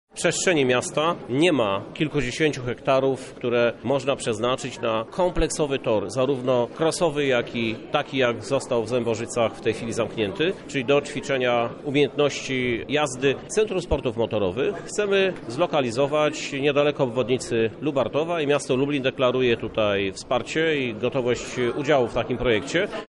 O szczegółach mówi Krzysztof Żuk prezydent miasta.